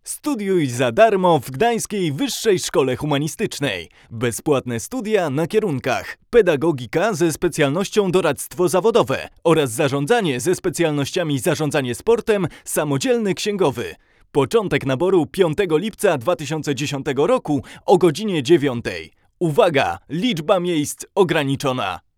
Nowa 67ka praktycznie nie szumi.